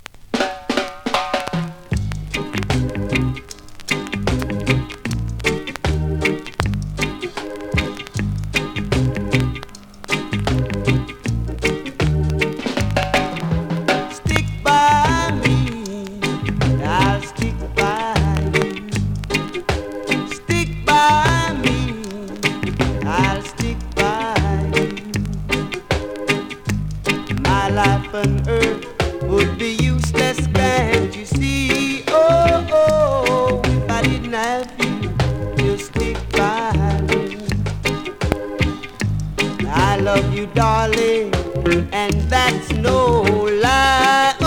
NEW IN!SKA〜REGGAE
スリキズ、ノイズそこそこありますが